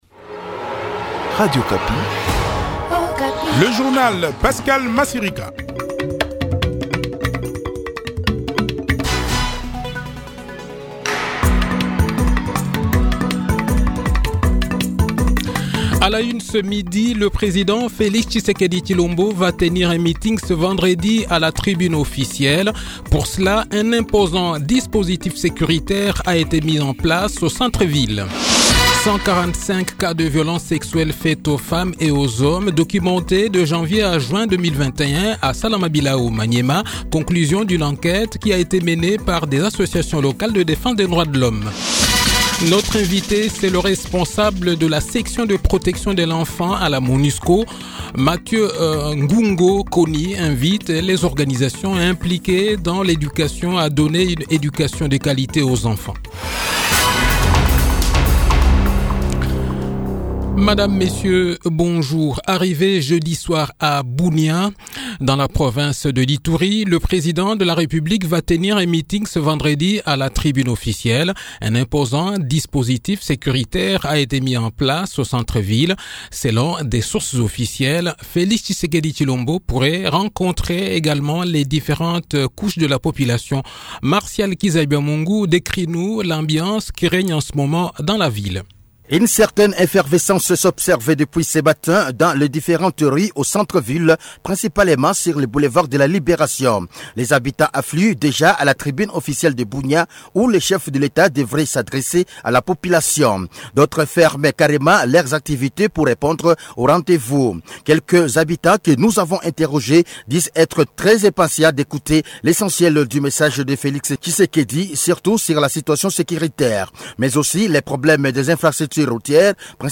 Le journal-Français-Midi
Le journal de 12 h, 18 Juin 2021